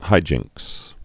(hījĭnks)